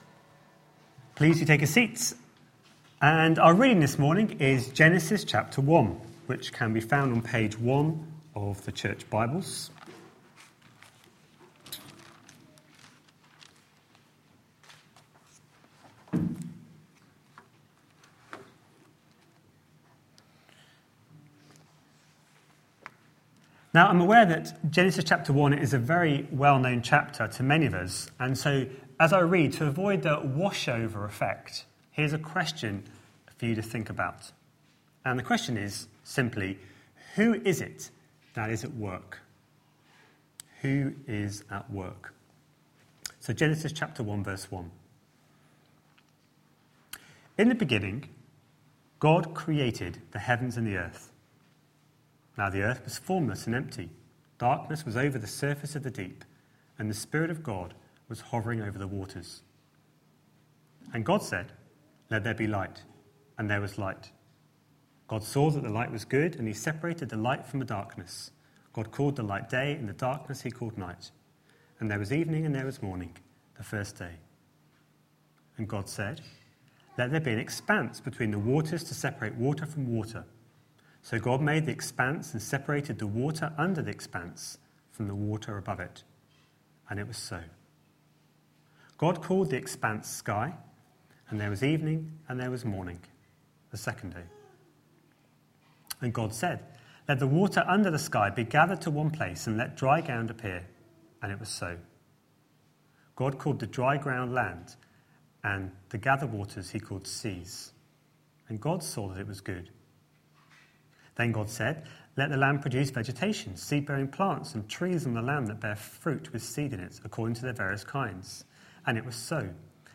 A sermon preached on 4th January, 2015, as part of our Work series.